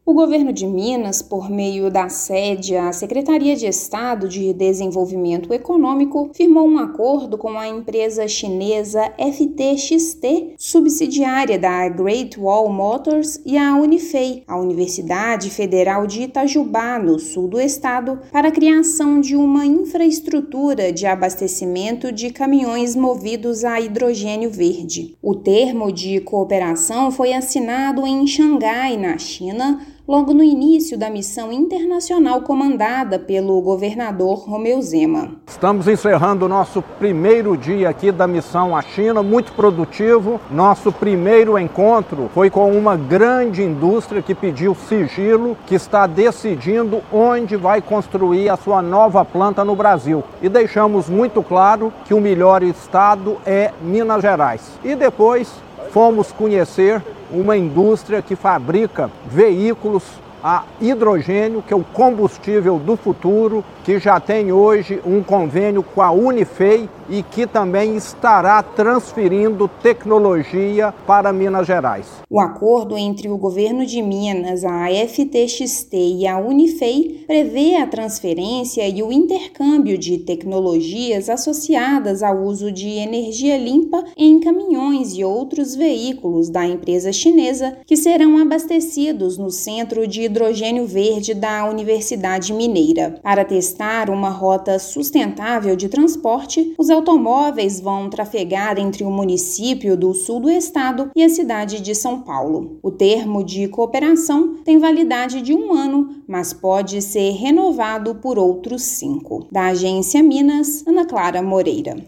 Memorando de Entendimento (MoU) prevê utilização do combustível renovável da Universidade pela FTXT e intercâmbio de conhecimento na área. Ouça matéria de rádio.